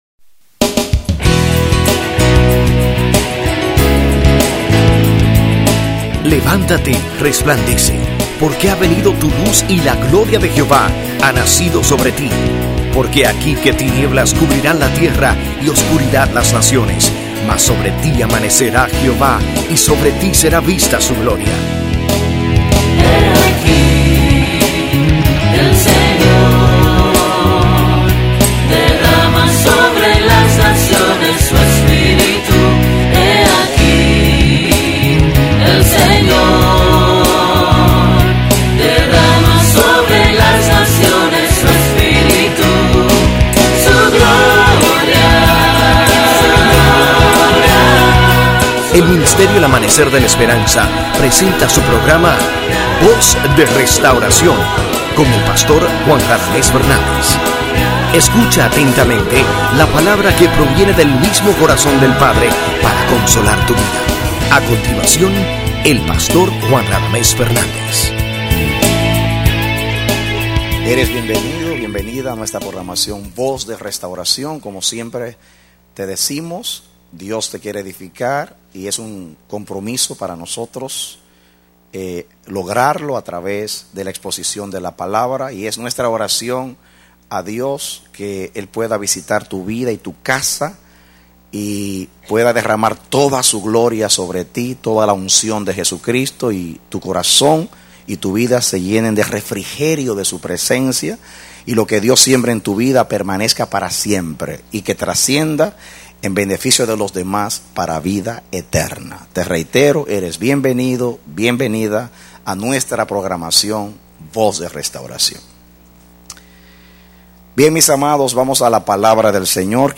Mensaje: “La Iglesia Saludable # 9”
Serie de 5 Predicado Noviembre 7, 2004